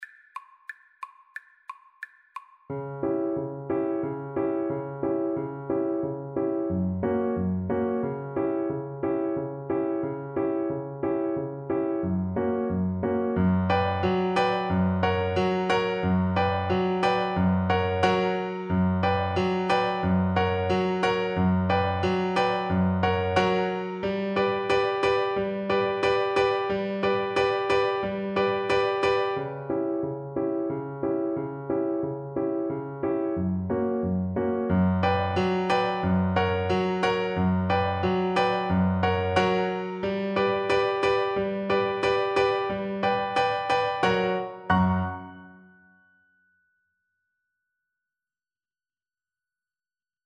Play (or use space bar on your keyboard) Pause Music Playalong - Piano Accompaniment Playalong Band Accompaniment not yet available transpose reset tempo print settings full screen
Presto = 180 (View more music marked Presto)
B minor (Sounding Pitch) (View more B minor Music for Violin )
2/4 (View more 2/4 Music)